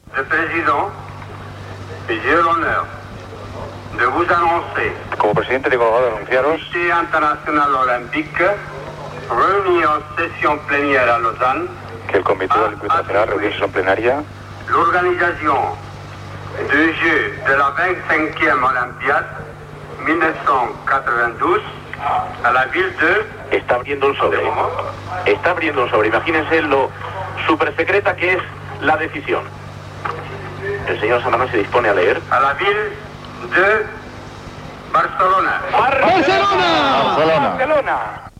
Juan Antonio Samaranch, president del Comitè Olímpic Internacional, comunica que Barcelona serà la seu dels Jocs Olímpics de 1992.
Informatiu